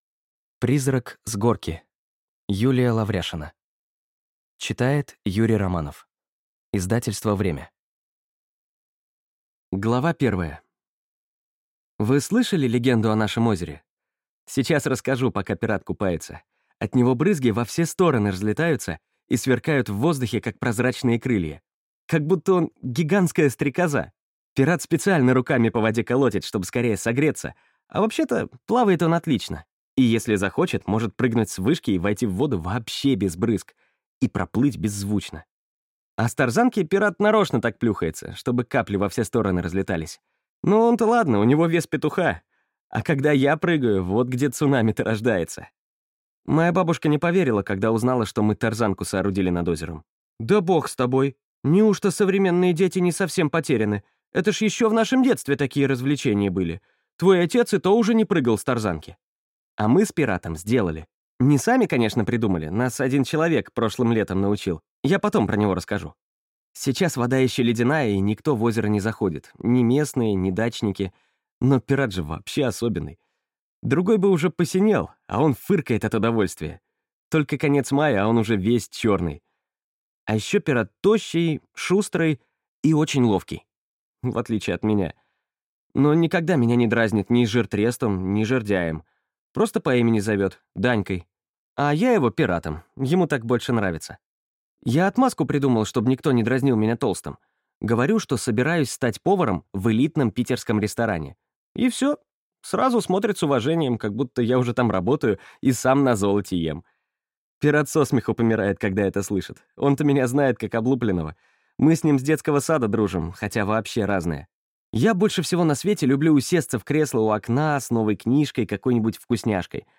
Аудиокнига Призрак с Горки | Библиотека аудиокниг